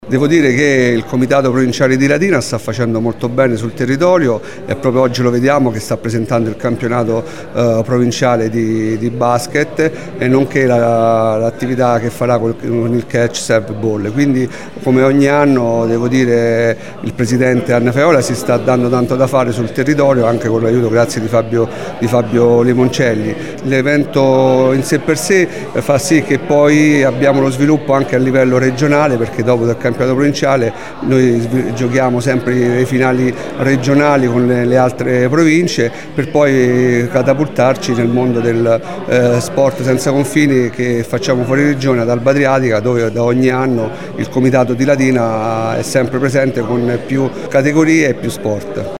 Ieri pomeriggio, presso la sala De Pasquale del Comune, la conferenza stampa di presentazione della prima edizione del Torneo di Pallavolo “Città di Latina”, che prenderà il via oggi con il Villaggio Europeo dello Sport.